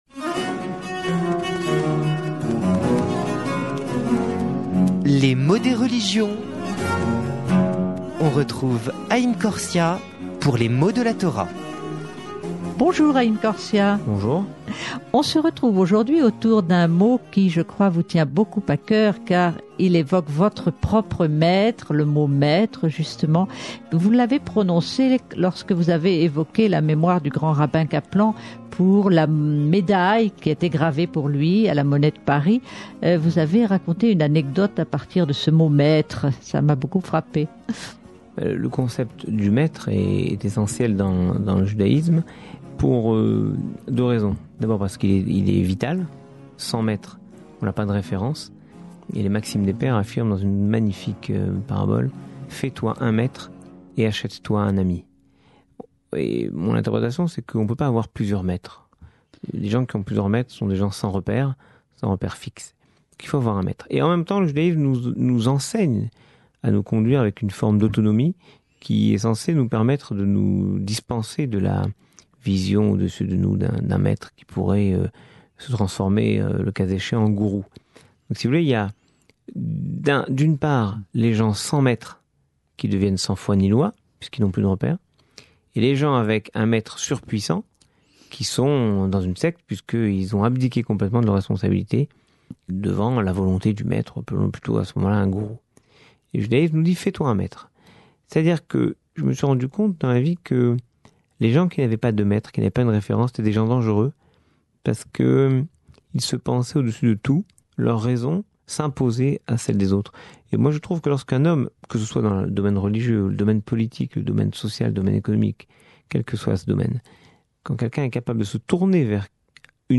avec Haïm Korsia, aumônier israélite de l’Armée de l’air et de l’Armée française.
Le rabbin Haïm Korsia explique ici le rôle du Maître, tant sur le plan spirituel qu’intellectuel.